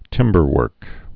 (tĭmbər-wûrk)